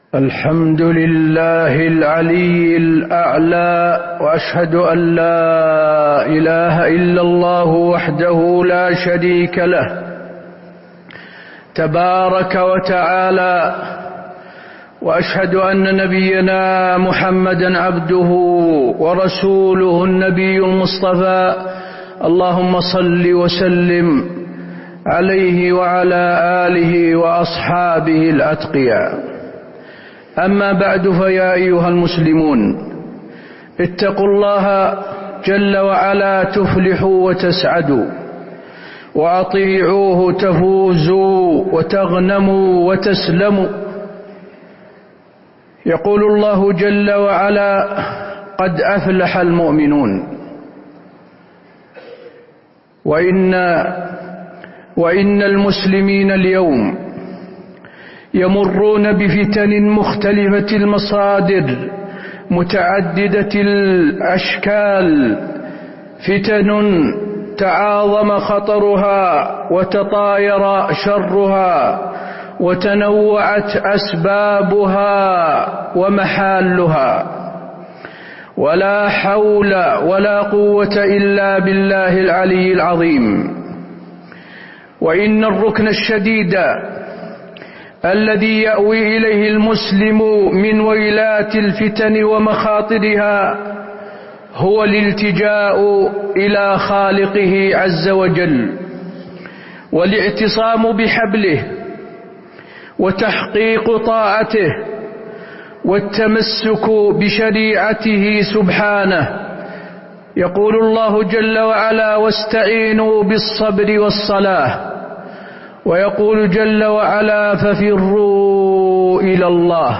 تاريخ النشر ٢٤ جمادى الأولى ١٤٤٥ هـ المكان: المسجد النبوي الشيخ: فضيلة الشيخ د. حسين بن عبدالعزيز آل الشيخ فضيلة الشيخ د. حسين بن عبدالعزيز آل الشيخ الوقاية من الفتن